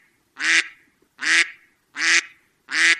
Crow Caw
Crow Caw is a free animals sound effect available for download in MP3 format.
343_crow_caw.mp3